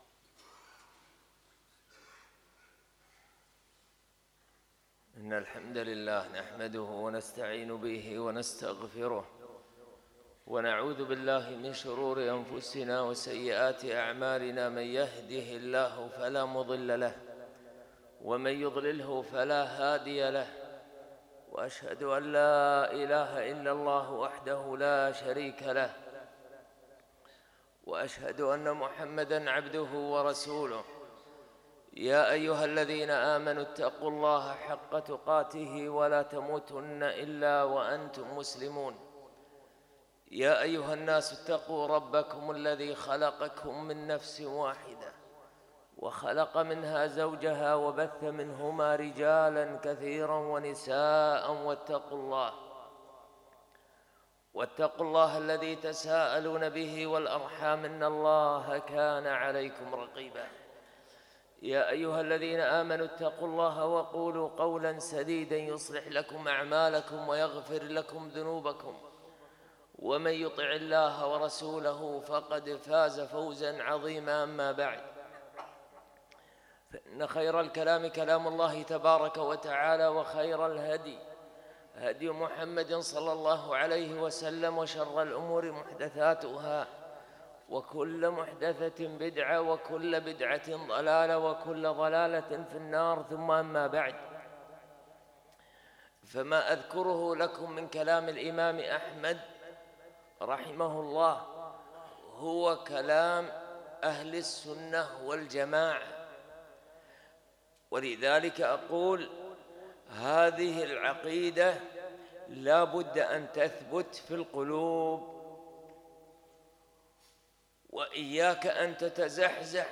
خطــب الجمــعة